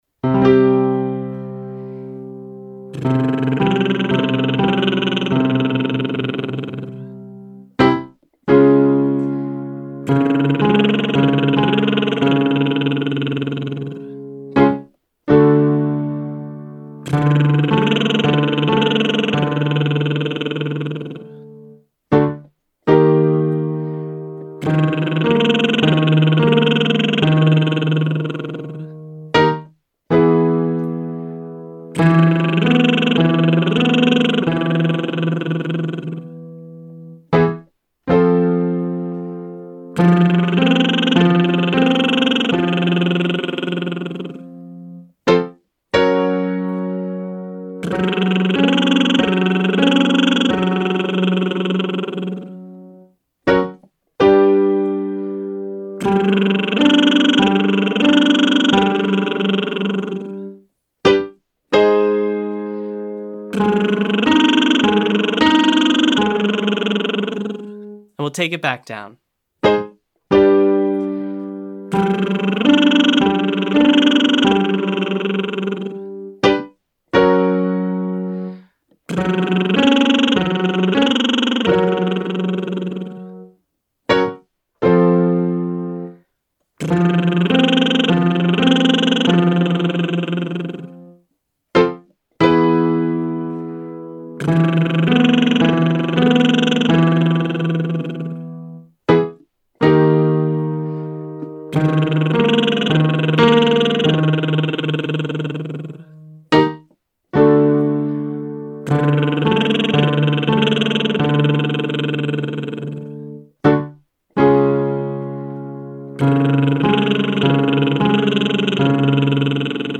Day 2: SOVT's - Online Singing Lesson
Exercise 2: Lip Trill 1-5-1-5-1